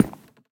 snapshot / assets / minecraft / sounds / block / cherry_wood_hanging_sign / step4.ogg
step4.ogg